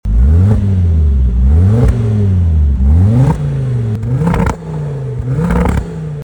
• 2.5L Inline 5-Cylinder Turbocharged (Petrol) Engine
Listen to this 5-Cylinder Brute!
• Militek Turbo Back Exhaust with Sports Cat (Resonated) (£2,200)
ford-focus-rs-mk2-ultimate-green-revo-stage-4-modified-gzn_Revs.mp3